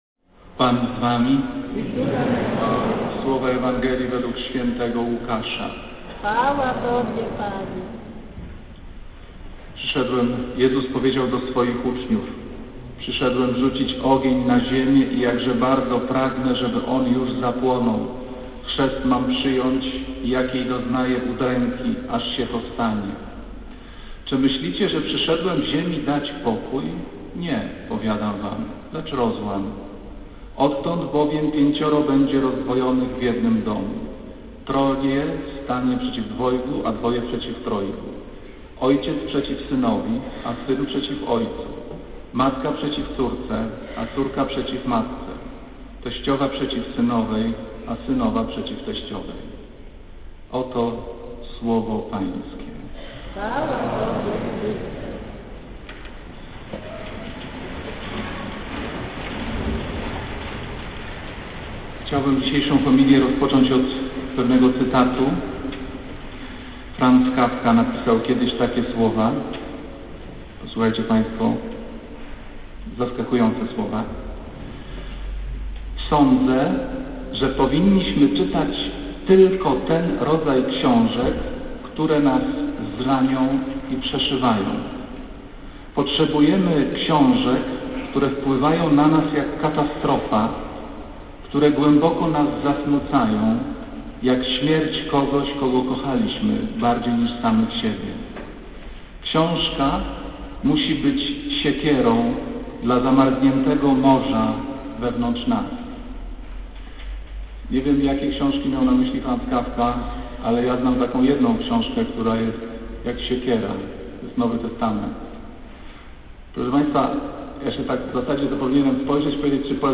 Kazanie z 19 sierpnia 2007r.
niedziela, godzina 15:00, kościół św. Anny w Warszawie